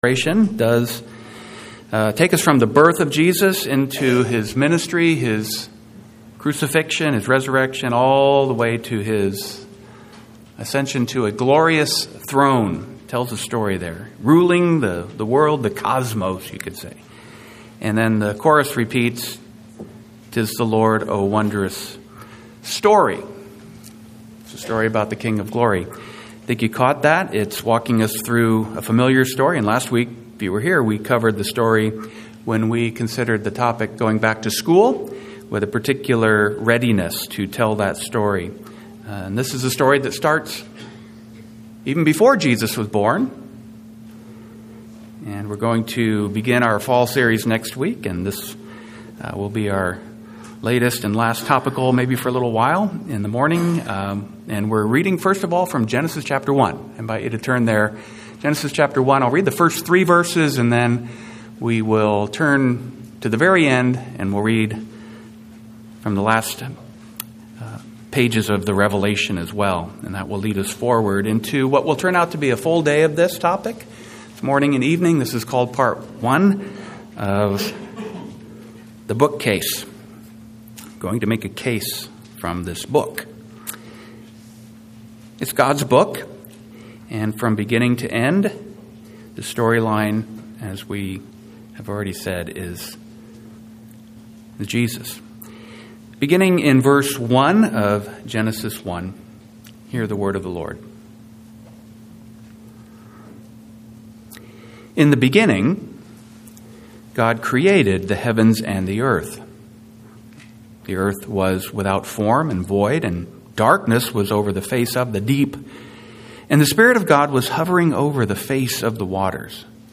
Occasional Sermons